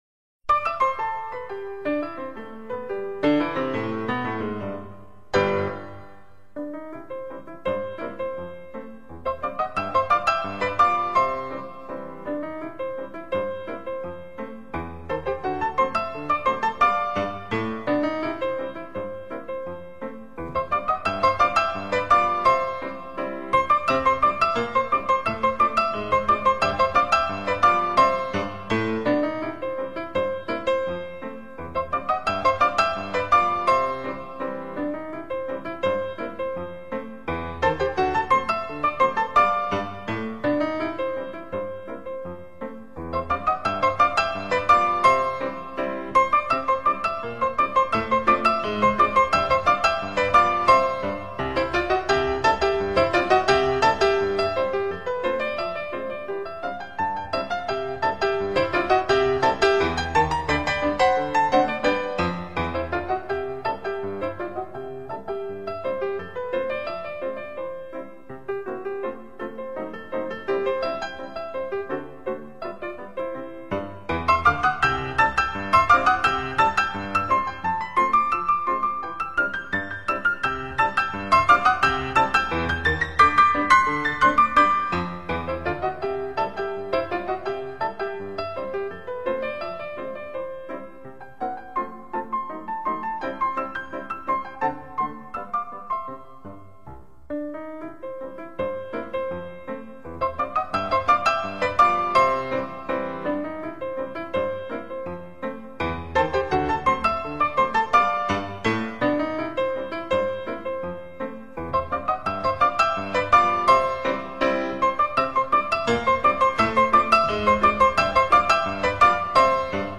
Классическая музыка